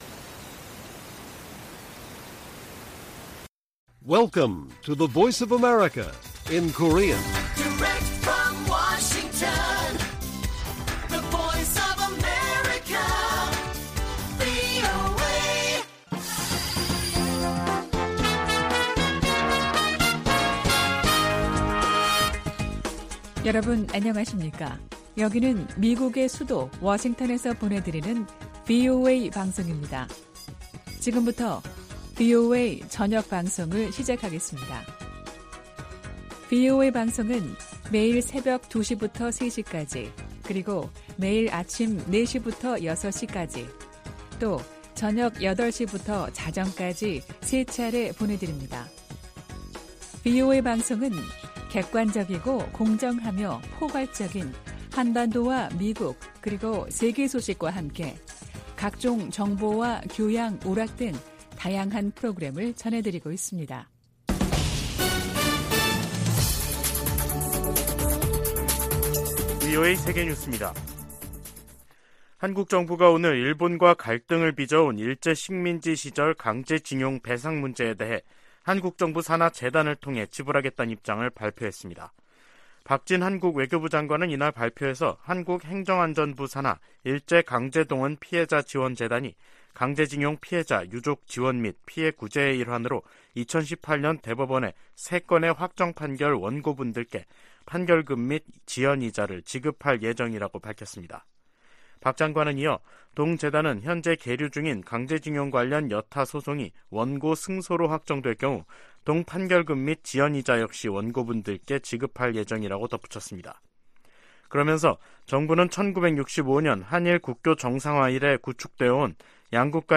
VOA 한국어 간판 뉴스 프로그램 '뉴스 투데이', 2023년 3월 6일 1부 방송입니다. 한국 정부가 일제 강제징용 해법으로 피해자들에게 국내 재단이 대신 배상금을 지급한다는 결정을 내렸습니다.